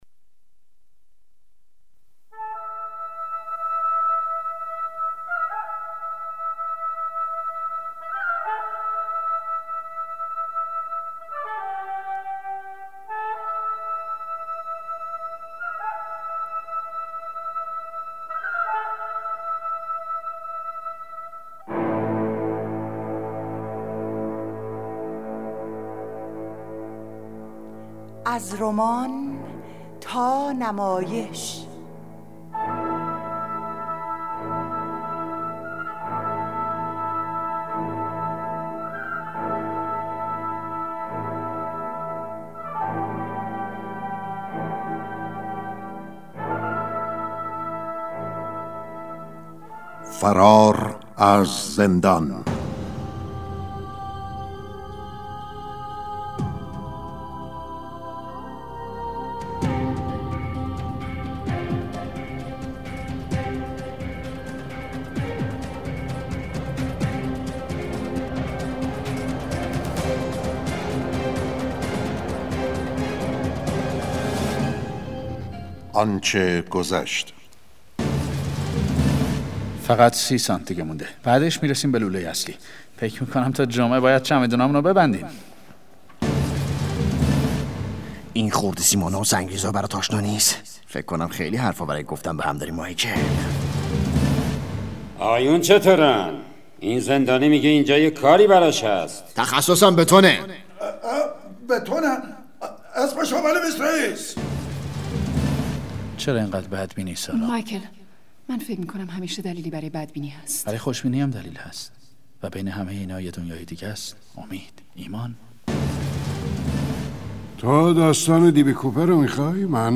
نمایش رادیویی «فرار از زندان»